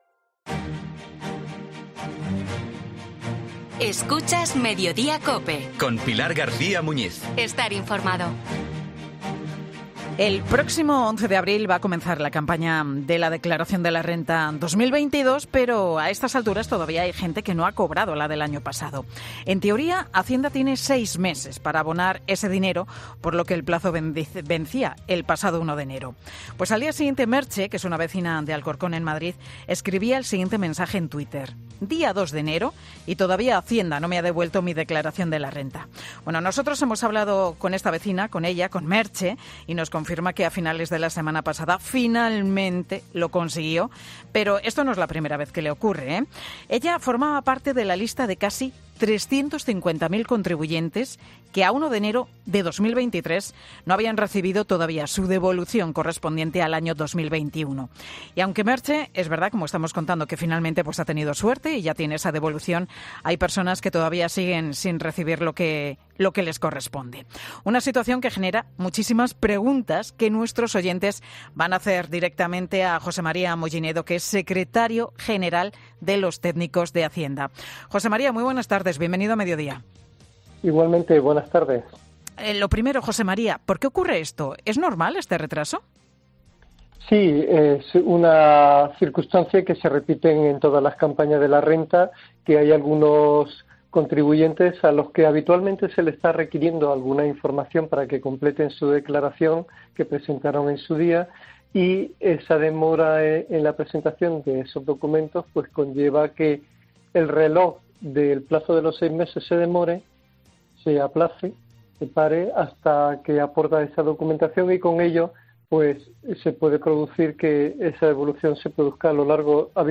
Los oyentes de 'Mediodía COPE' preguntan al experto de Hacienda: ¿Qué ocurre con mi devolución de 2022?